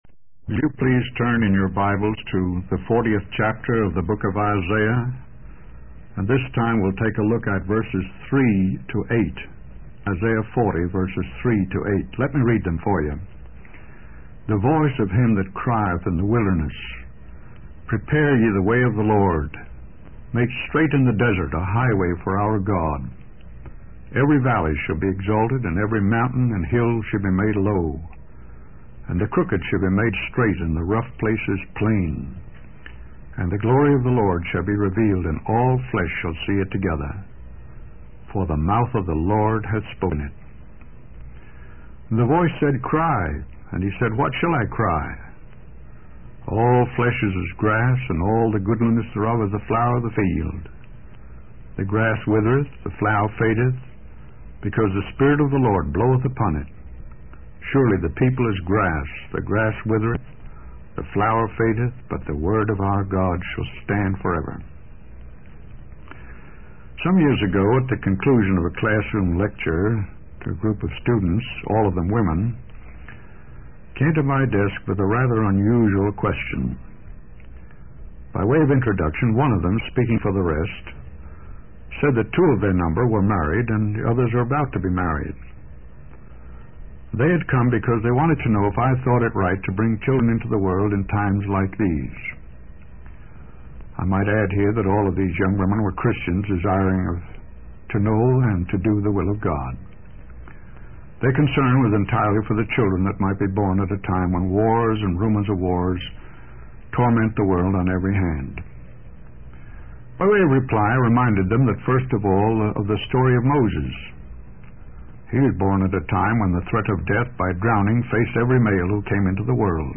In this sermon, the preacher focuses on Isaiah 40:3-8, which speaks about preparing the way for the Lord. He emphasizes that sin is the root cause of all ruin and devastation in the world.